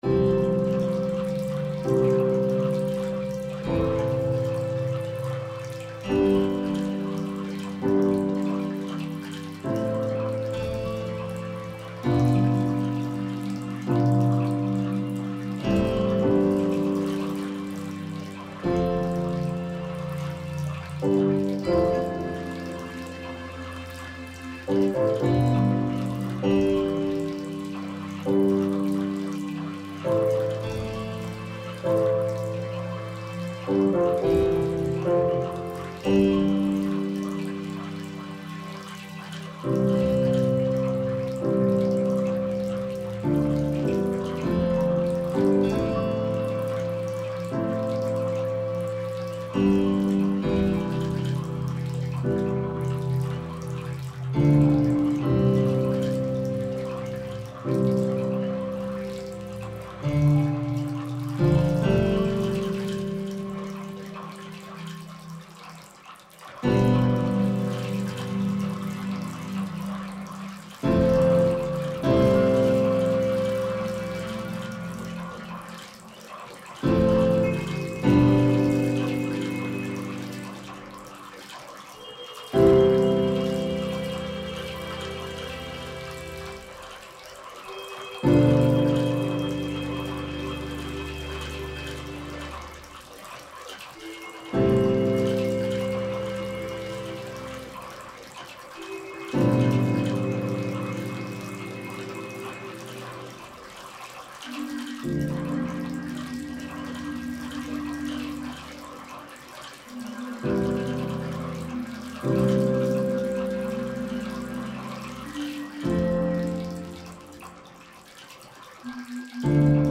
BGM
スローテンポ穏やか